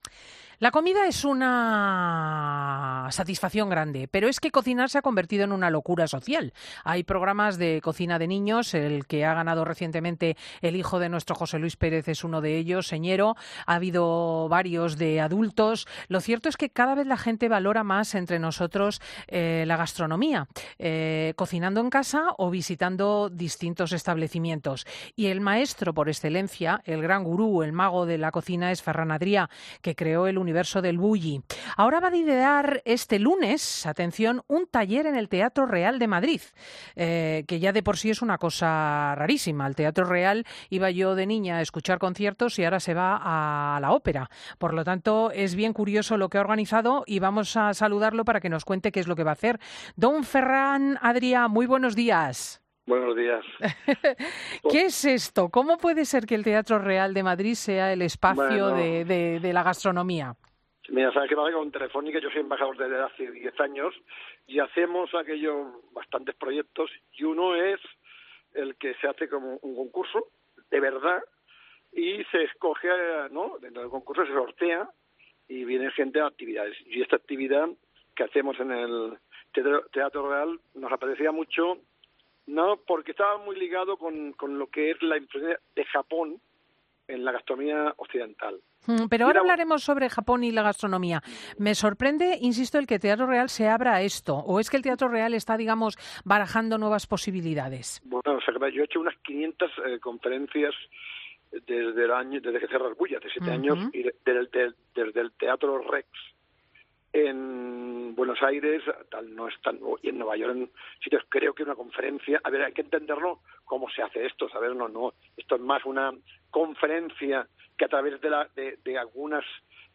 Cristina López Schlichting habla con el cocinero Ferrán Adriá que debutará este lunes en el Teatro Real para ofrecer una clase magistral ante 700...